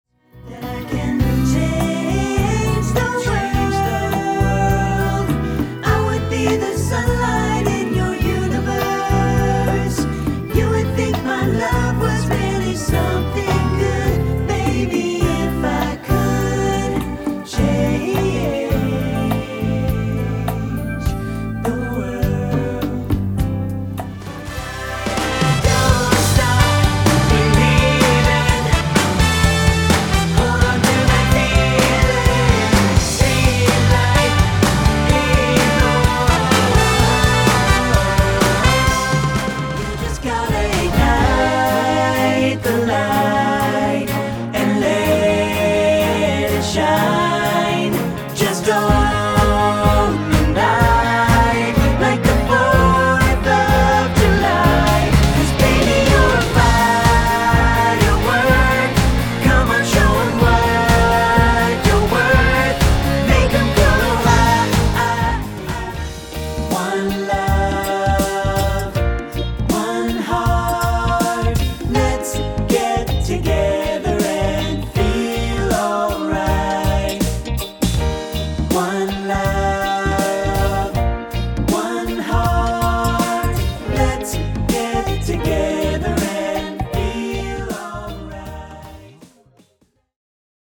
SAB